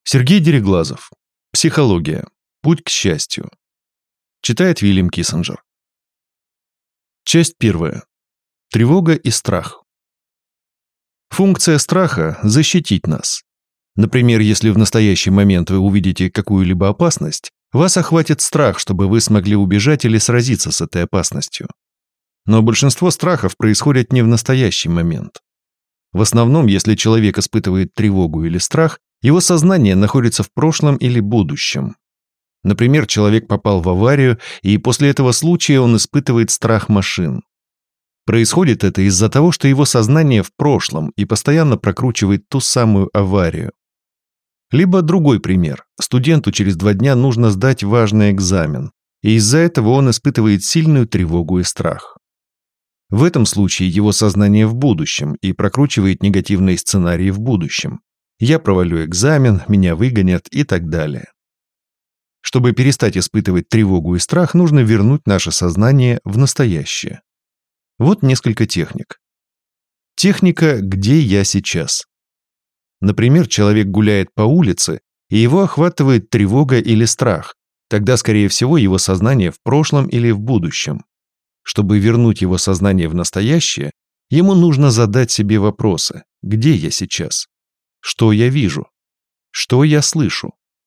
Аудиокнига Психология: Путь к счастью | Библиотека аудиокниг